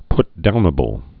(ptdounə-bəl)